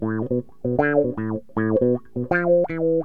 wah.mp3